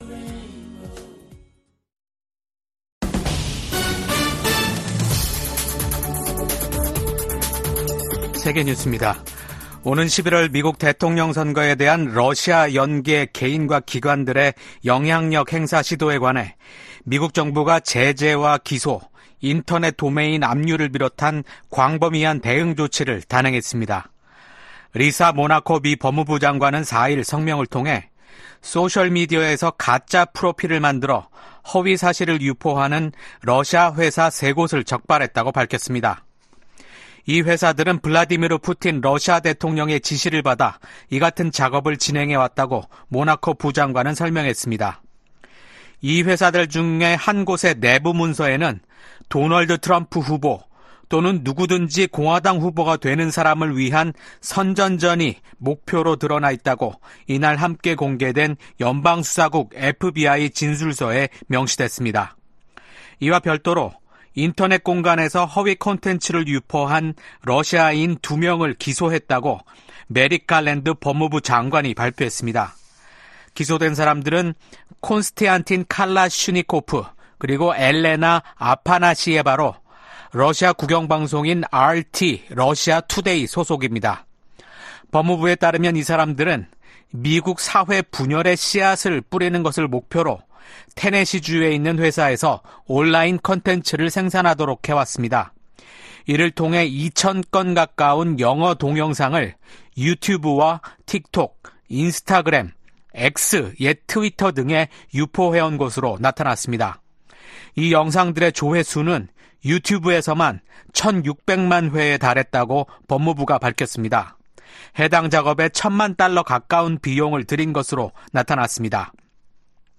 VOA 한국어 아침 뉴스 프로그램 '워싱턴 뉴스 광장' 2024년 9월 6일 방송입니다. 미국과 한국이 북한의 도발에 대한 강력한 대응 의지를 재확인했습니다. 북한이 25일만에 또 다시 한국을 향해 쓰레기 풍선을 날려 보냈습니다. 국제 핵실험 반대의 날을 맞아 북한의 핵과 미사일 개발을 규탄하는 목소리가 이어졌습니다.